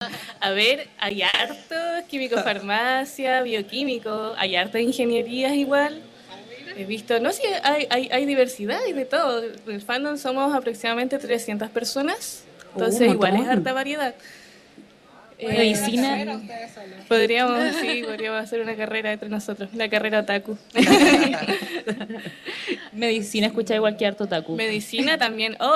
En ese sentido, en el programa especial que realizó Libros al Aire el pasado 4 de octubre, en vivo desde la Plaza del Estudiante UdeC, una de las integrantes de Tinta Negra reconoció que uno de los tópicos que más se repite en torno a sus lecturas y escrituras es el “desamor”.